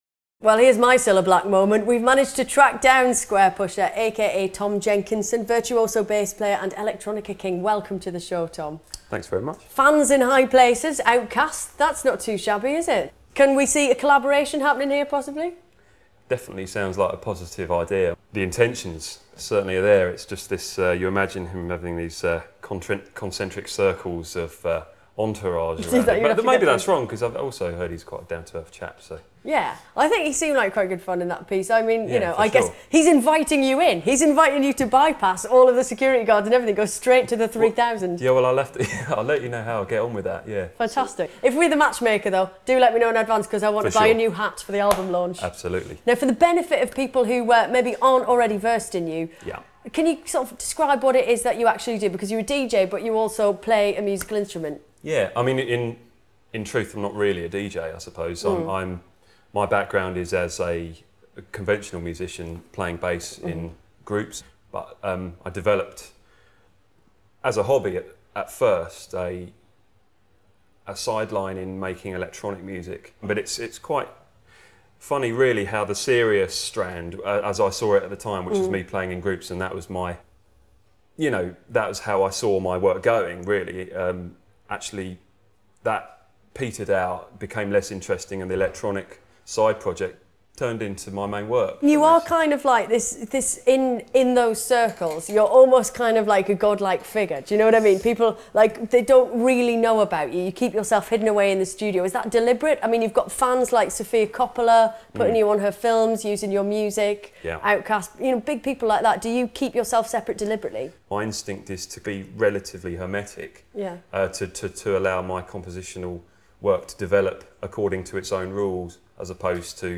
Squarepusher on the BBC2 Culture Show Part 2 Interview.flac